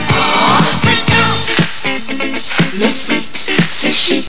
home *** CD-ROM | disk | FTP | other *** search / AMOS PD CD / amospdcd.iso / 051-075 / apd058 / freak_out ( .mp3 ) < prev next > Amiga 8-bit Sampled Voice | 1990-10-25 | 62KB | 1 channel | 14,435 sample rate | 4 seconds